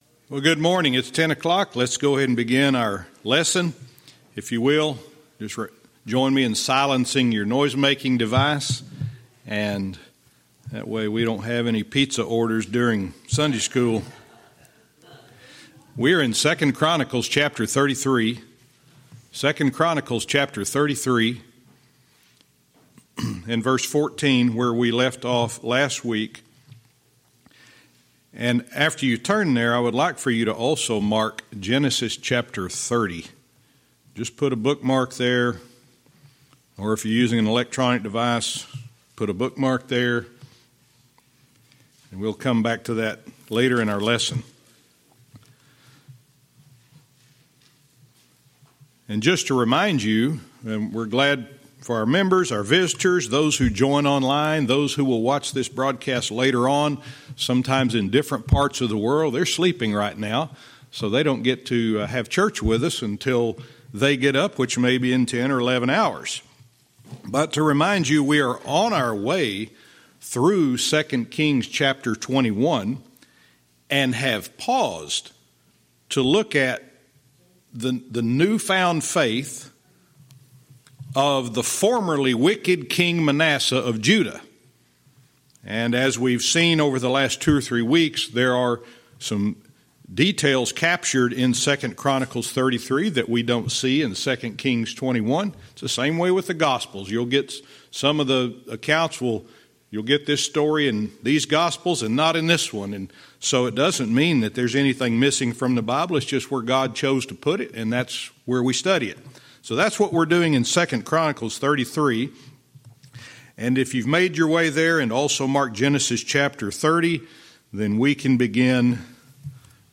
Verse by verse teaching - 2 Kings 21:18(cont) & 2 Chronicles 33:14-17